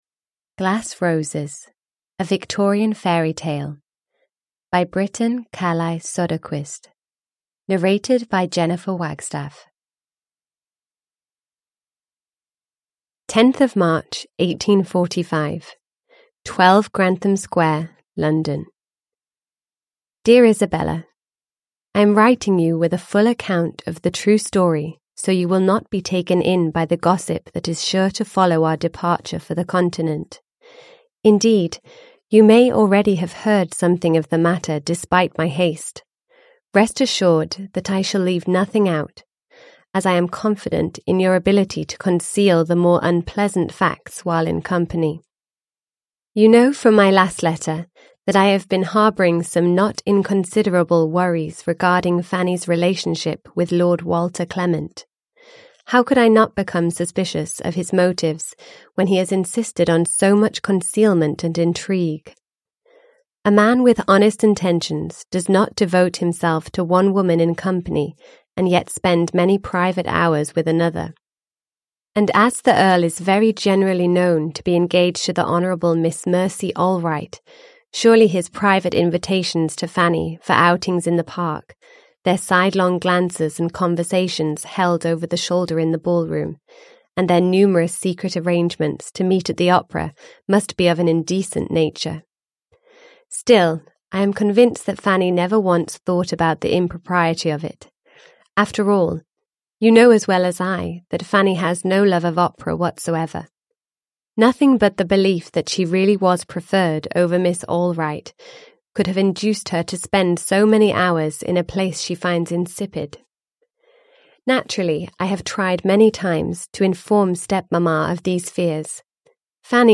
Glass Roses – Ljudbok